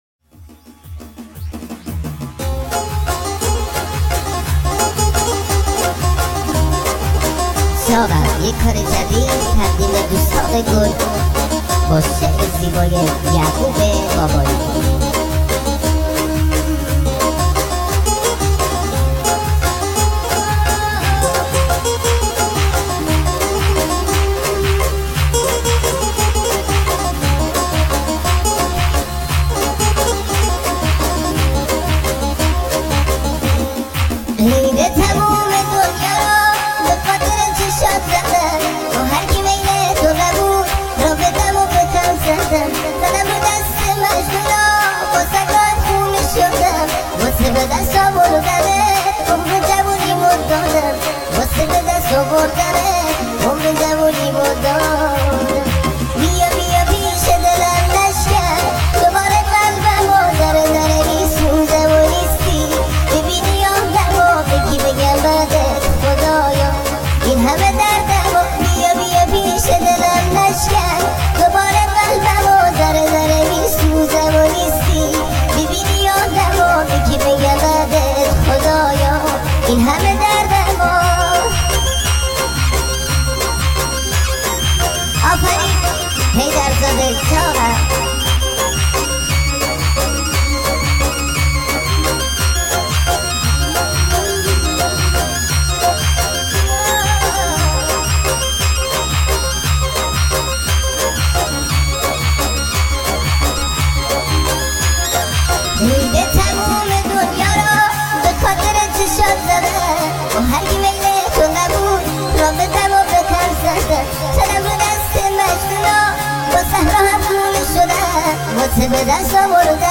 ریمیکس شده با صدای نازک شده بچگانه بچه پسر و دختر